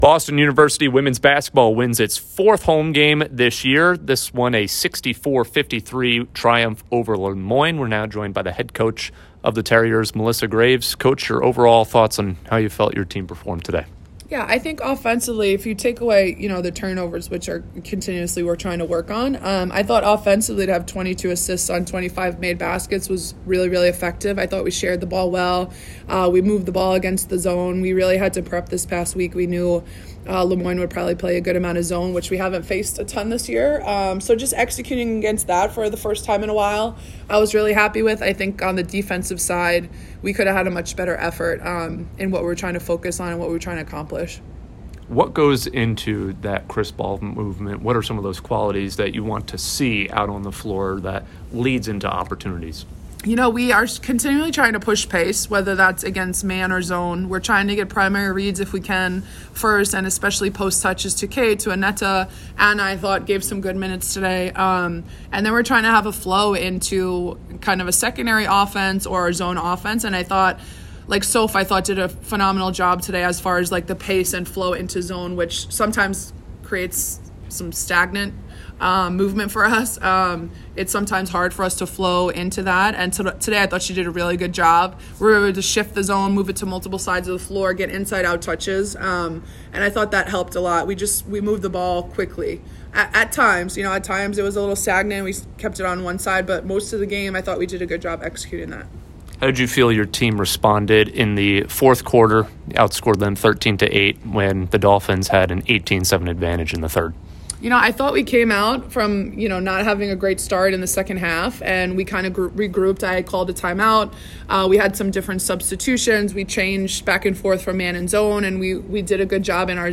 WBB_Le_Moyne_Postgame.mp3